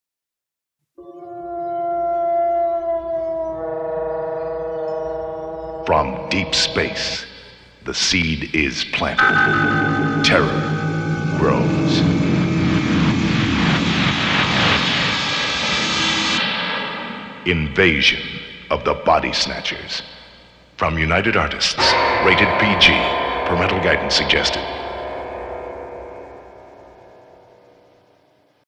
Radio Spots
The stereo spots are basically the same with minor differences, but the mixing is a little sharper and the background effects are more pronounced.